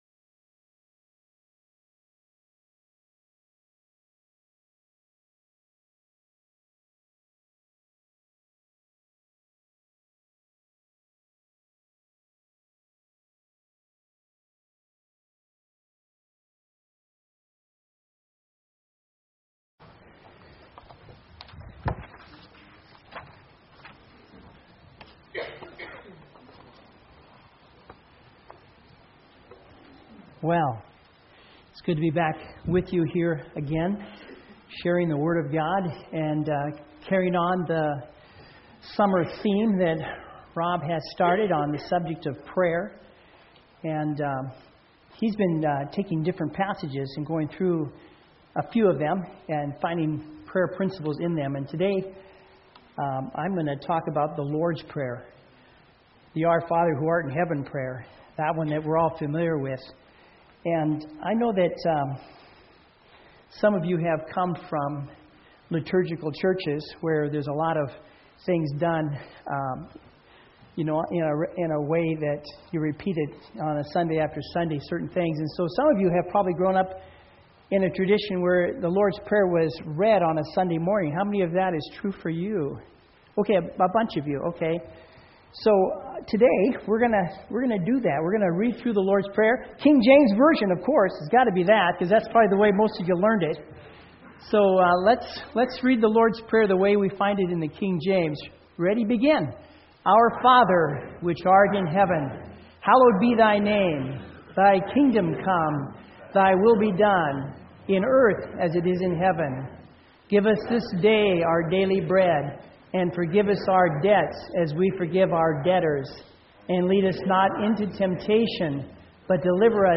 SERMONS Seven Petitions of the Lord's Prayer Pray It Forward - A Series on Prayer July 2